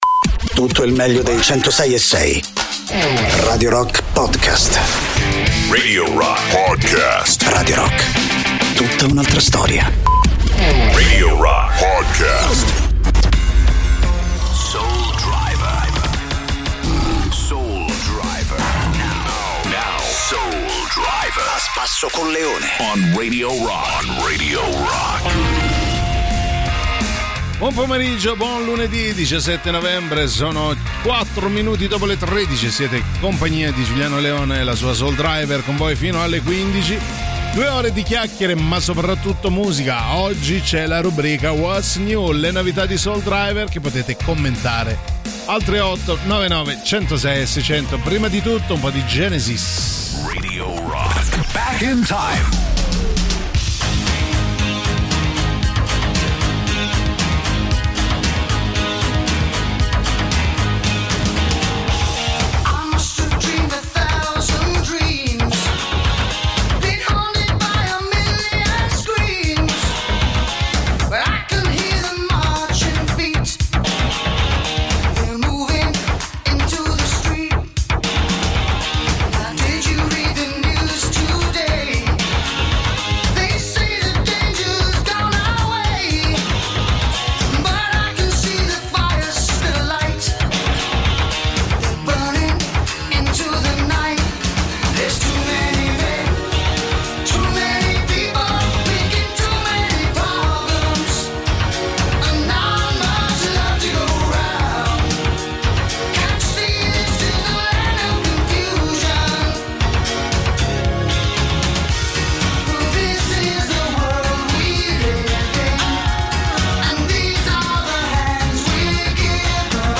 in diretta dal lunedì al venerdì, dalle 13 alle 15